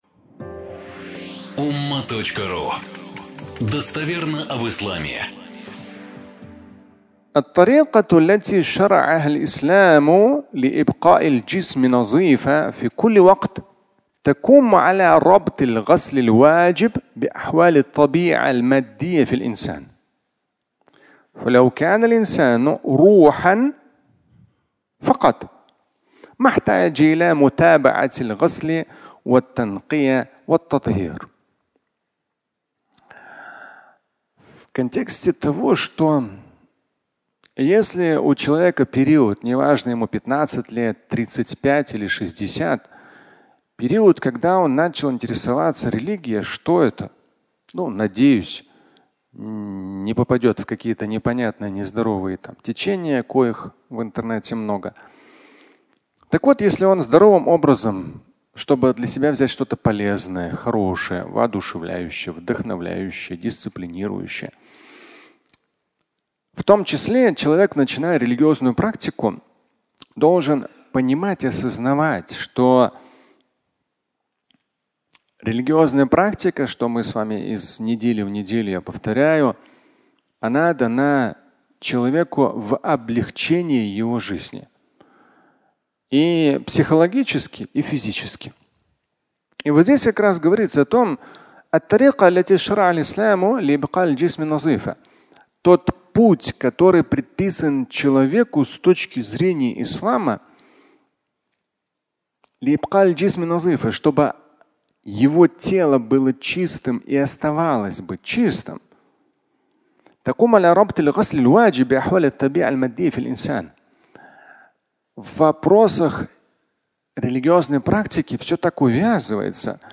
Религиозная практика (аудиолекция)
Фрагмент пятничной лекции